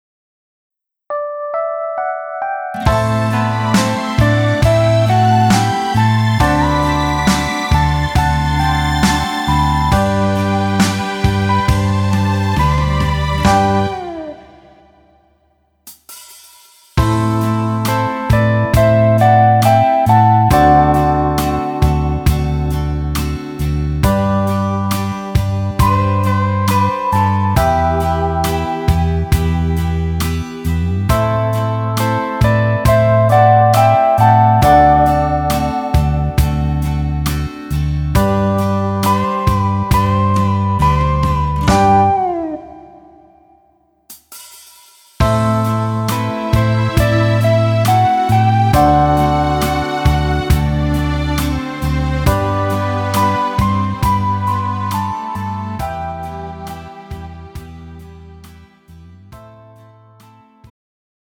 음정 여자키
장르 가요 구분 Pro MR